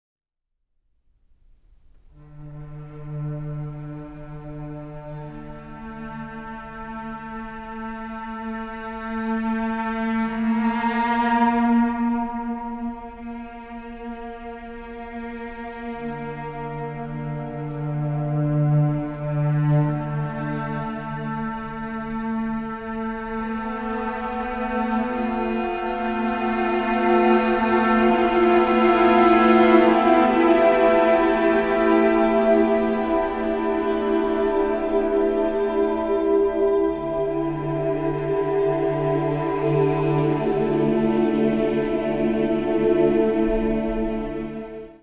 for two cellos and male choir